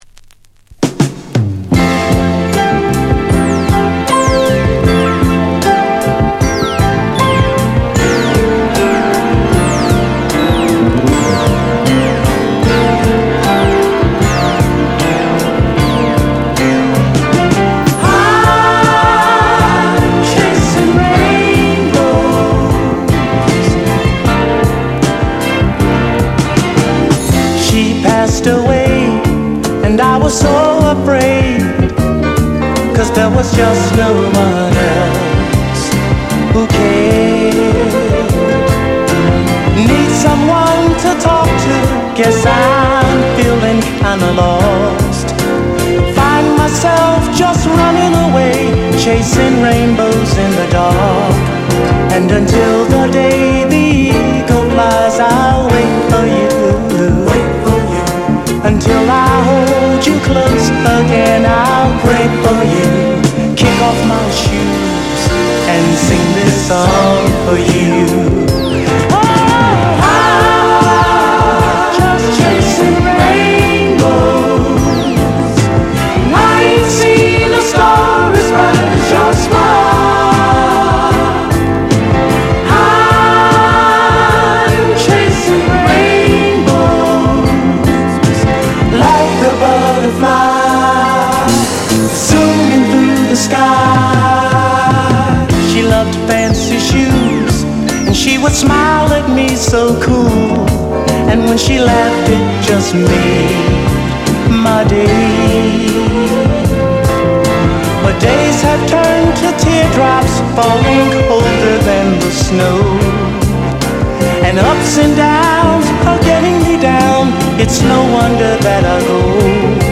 フィラデルフィアのヴォーカル・グループ
• 特記事項: STEREO / DJ